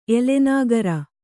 ♪ elenāgara